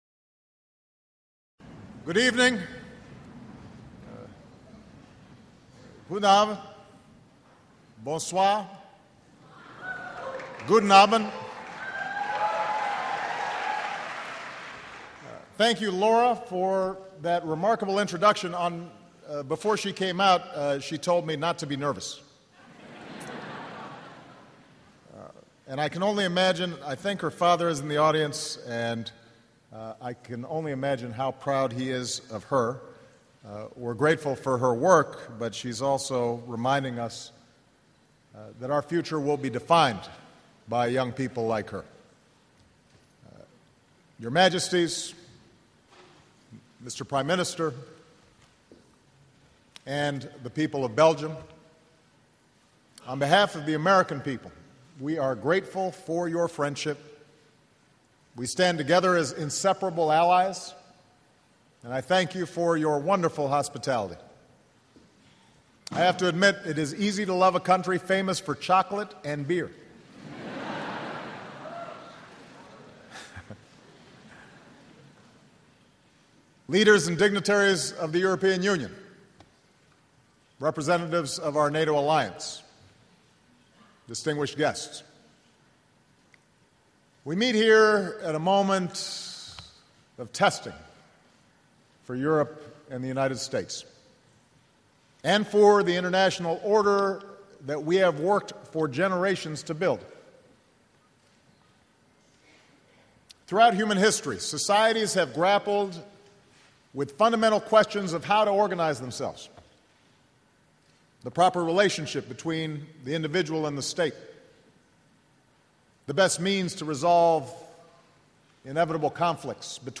U.S. President Barack Obama speaks during a three-nation, four-day European visit